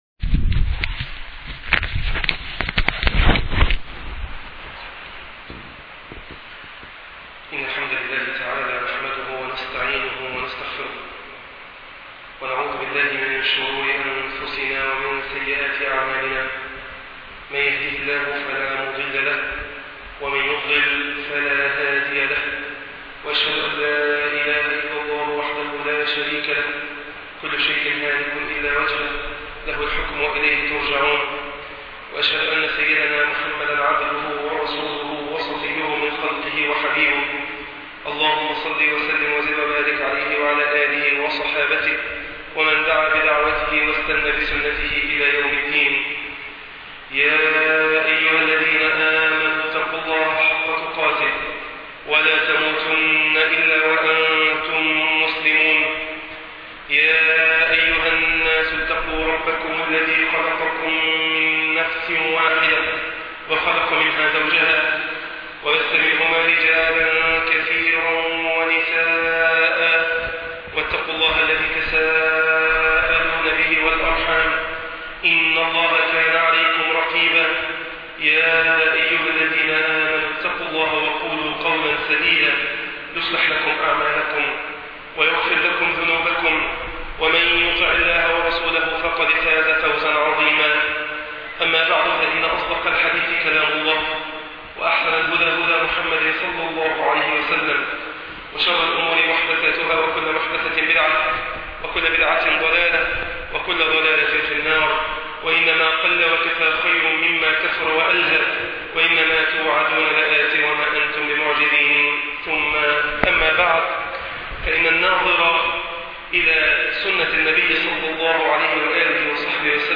تفسير سوره الفتح { خطب الجمعه